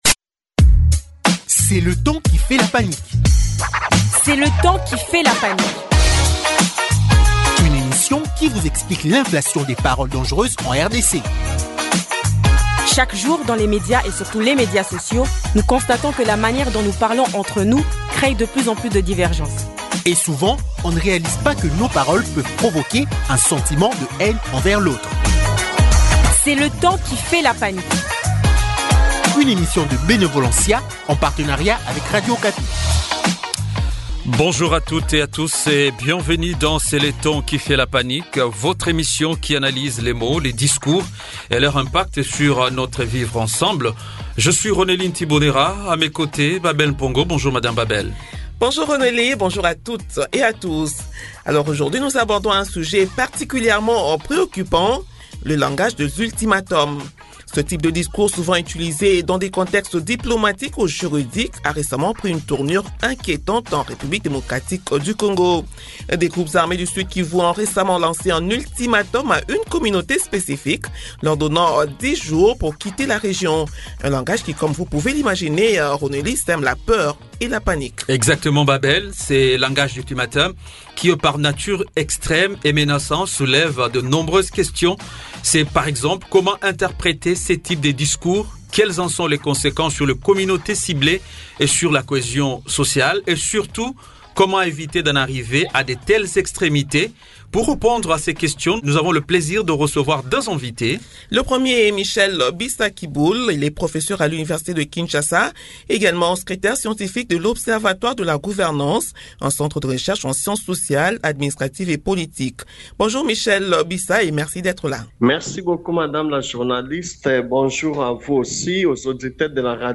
Pour en parler, deux invités :